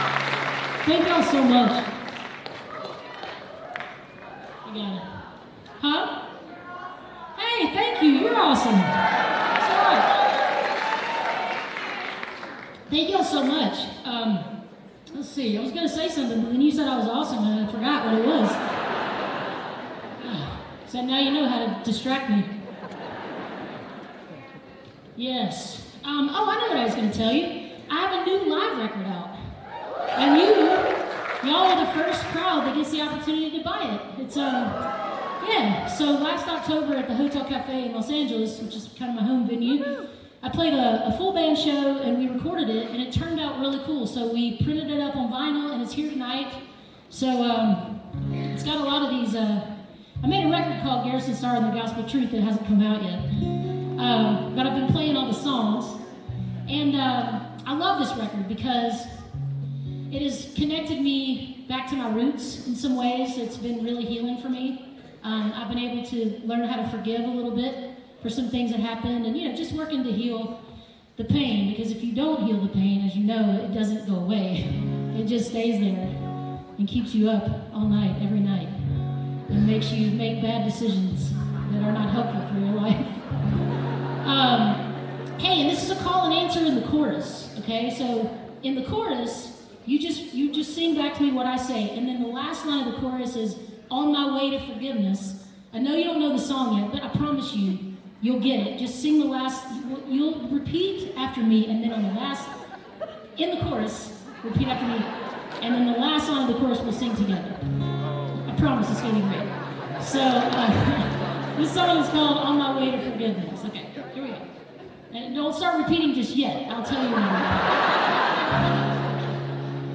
(band show)
(captured from a web stream)